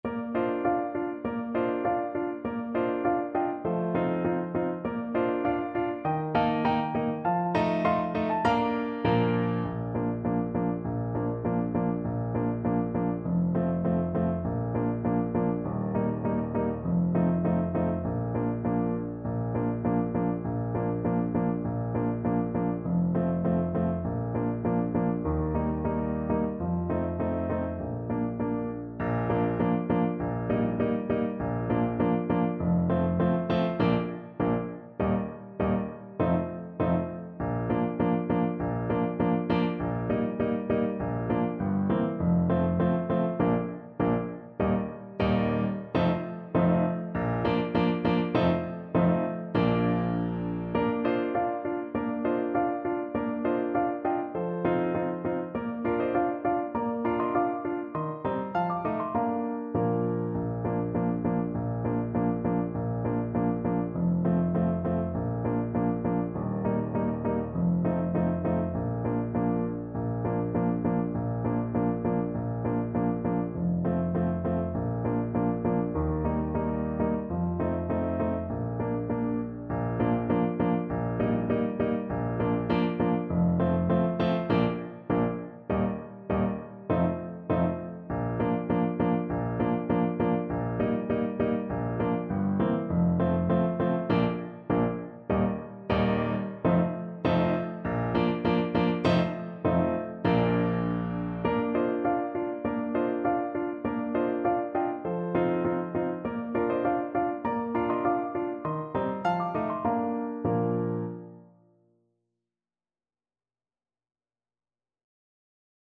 Trumpet
Bb major (Sounding Pitch) C major (Trumpet in Bb) (View more Bb major Music for Trumpet )
4/4 (View more 4/4 Music)
Moderato
Bb4-D6
Pop (View more Pop Trumpet Music)
nelly_blyTPT_kar1.mp3